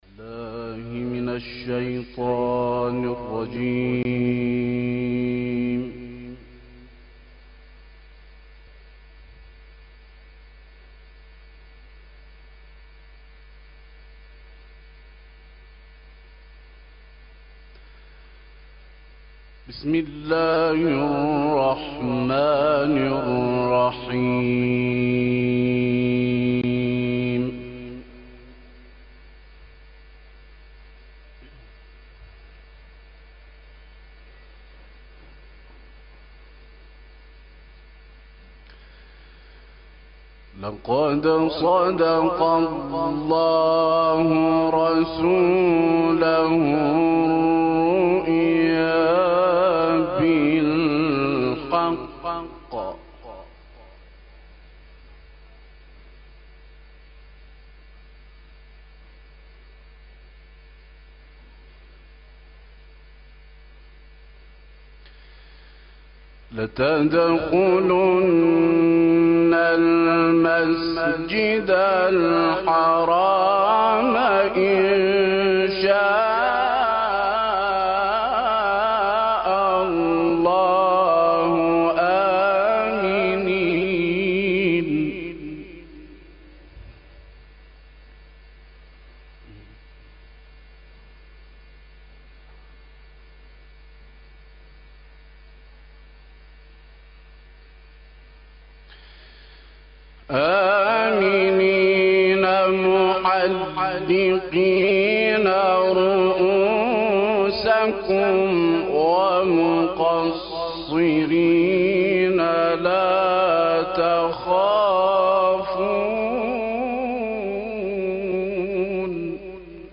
به گزارش خبرنگار فرهنگی باشگاه خبرنگاران پویا، مراسم افتتاحیه سی و چهارمین دوره مسابقات بین المللی قرآن کریم ساعتی پیش در مصلای امام خمینی(ره) با حضور 276 متسابق از 83 کشور اسلامی برگزار شد.